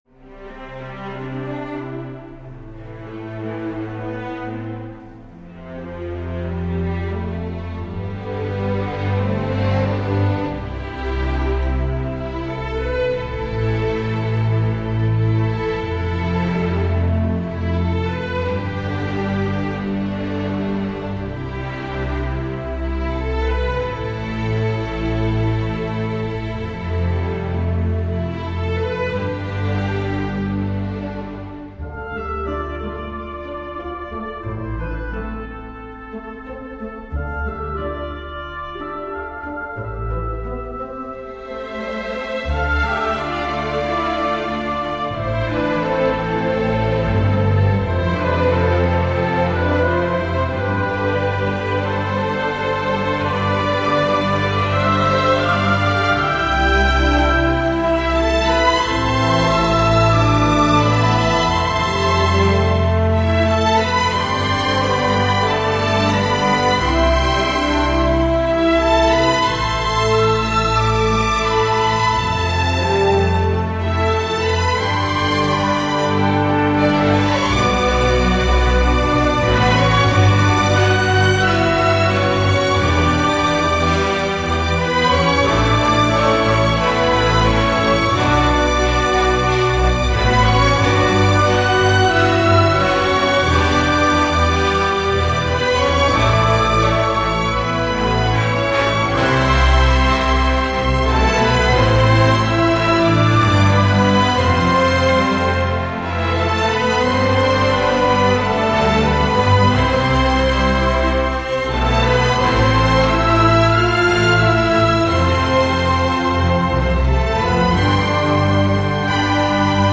Les bois et la harpe ont un beau rôle à jouer.